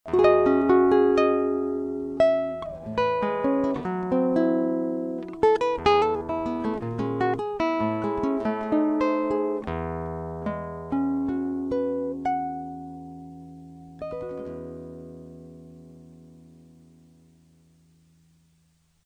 A seguir são disponibilizados alguns pequenos arquivos MP3 demonstrando a sonoridade original e a sonoridade após a equalização.
Violão Takamine
Música (sem eq.)